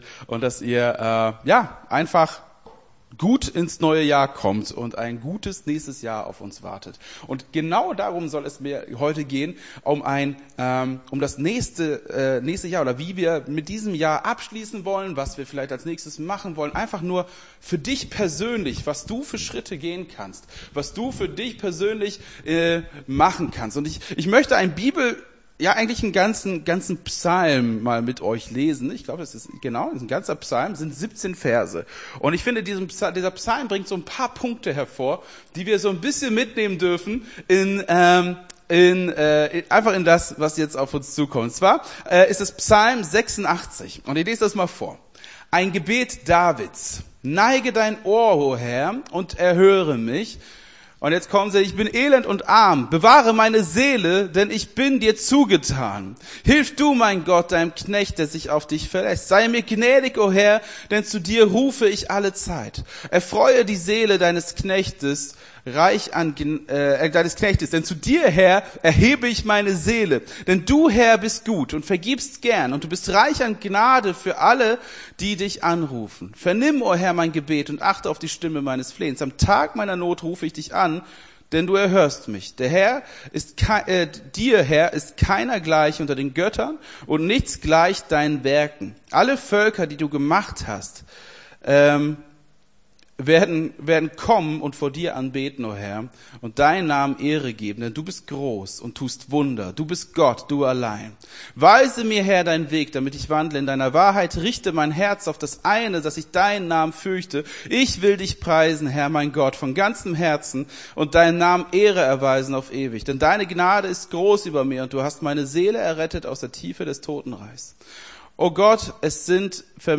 Gottesdienst 31.12.23 - FCG Hagen